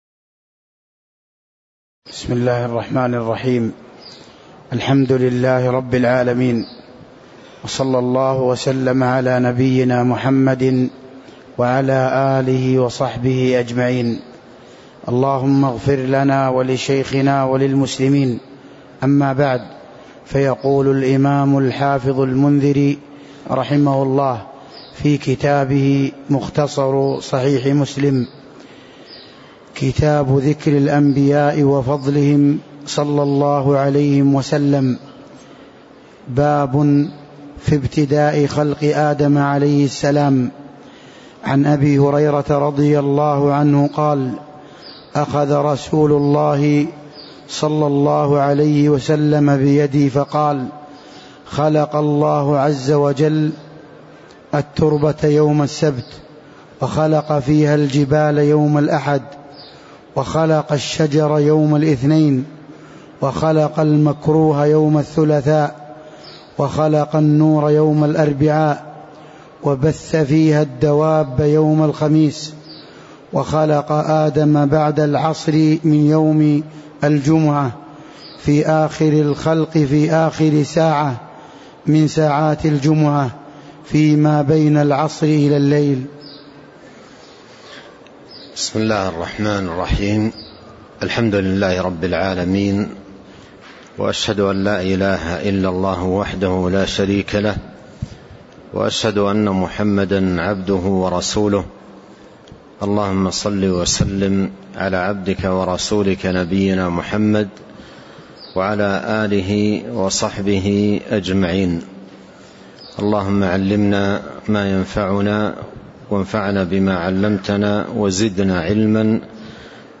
تاريخ النشر ٤ رمضان ١٤٤٣ هـ المكان: المسجد النبوي الشيخ